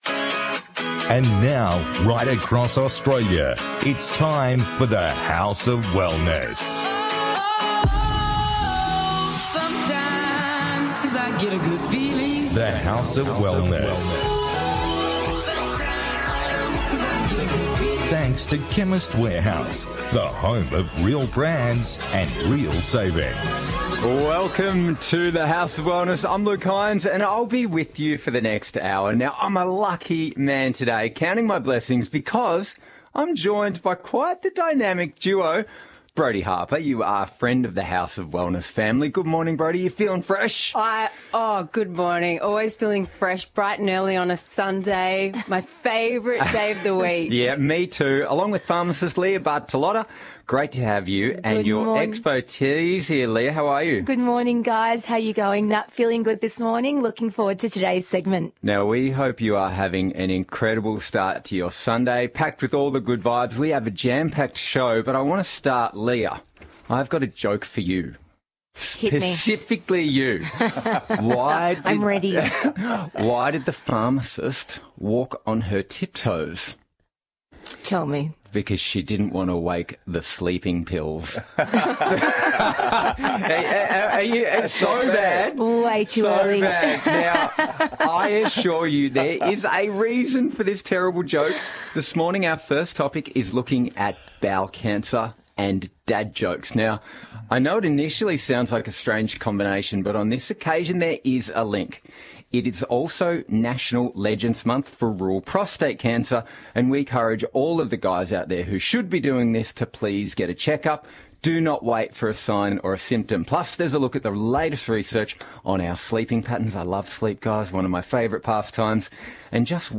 On this week’s The House of Wellness radio show: